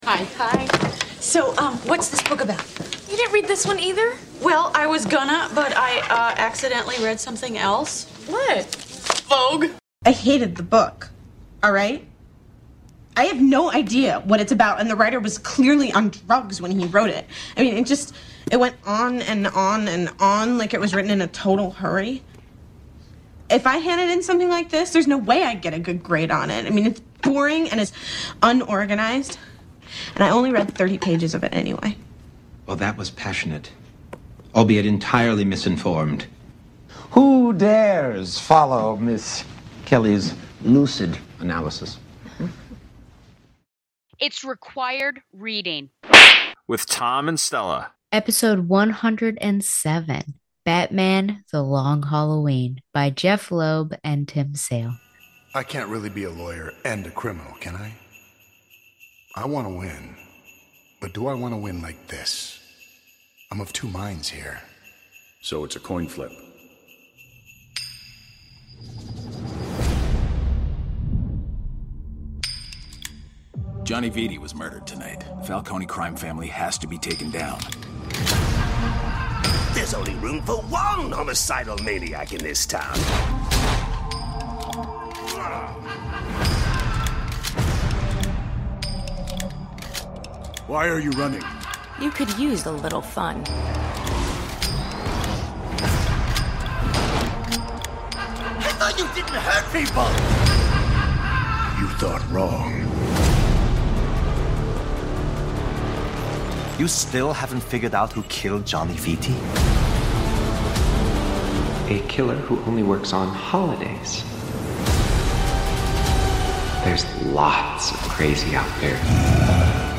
is two teachers talking about literature. Each episode, we will be taking a look at a single work, analyzing it, criticizing it and deciding if it’s required reading.